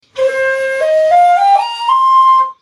SONS ET LOOPS DE SHAKUHACHIS GRATUITS
Shakuhachi 44